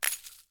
Bullet Shell Sounds
generic_leaves_8.ogg